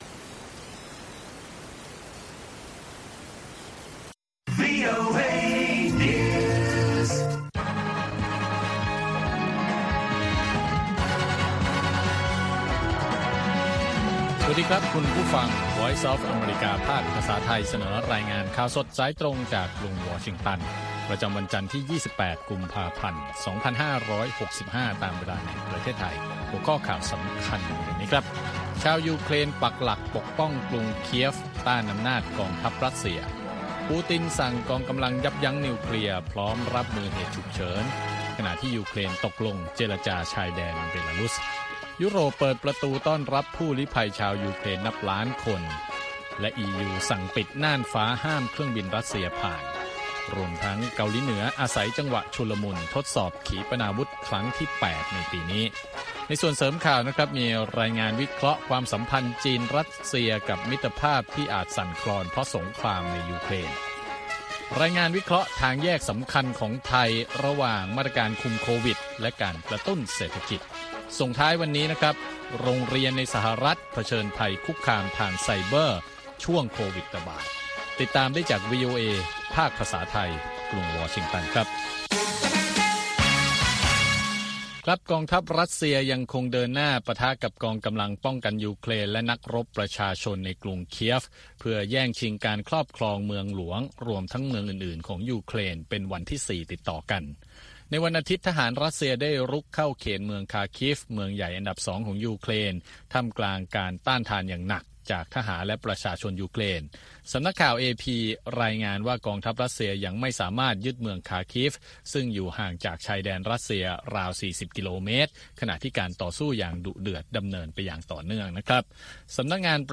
ข่าวสดสายตรงจากวีโอเอ ภาคภาษาไทย 6:30 – 7:00 น. ประจำวันจันทร์ที่ 28 กุมภาพันธ์ 2565 ตามเวลาในประเทศไทย